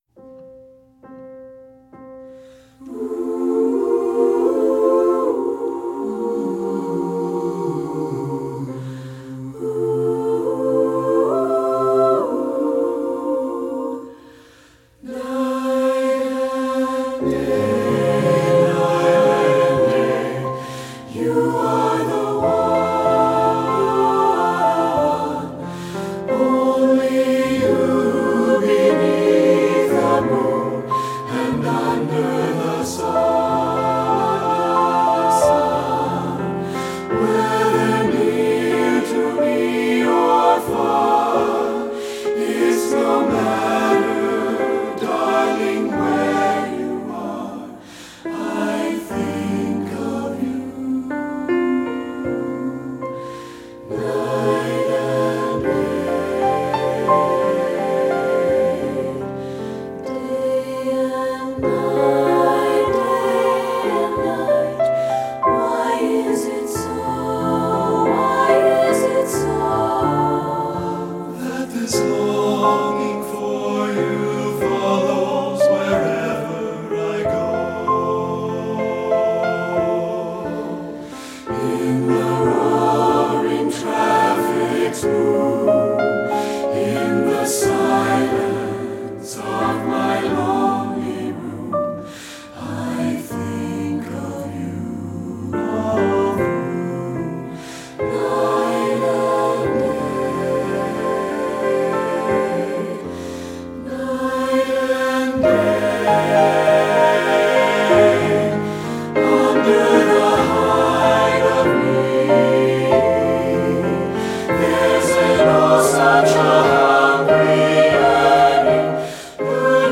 choral
SSATB (SATB recording)